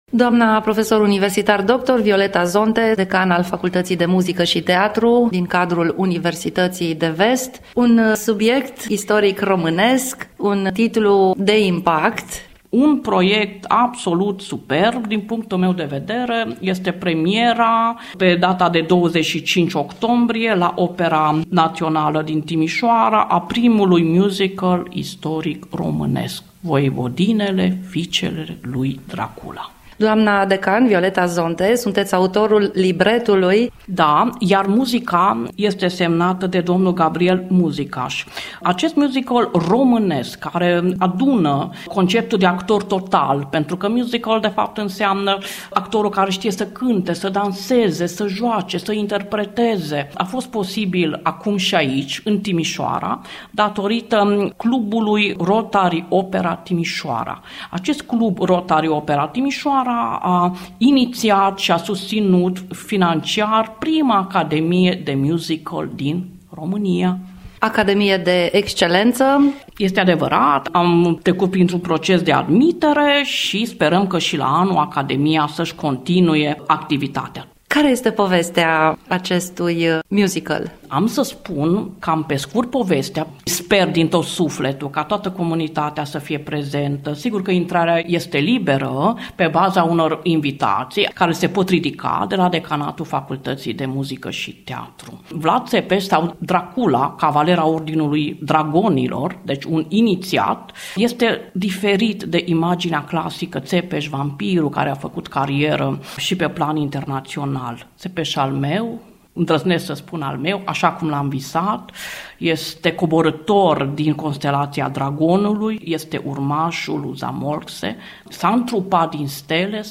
Detalii, în dialogul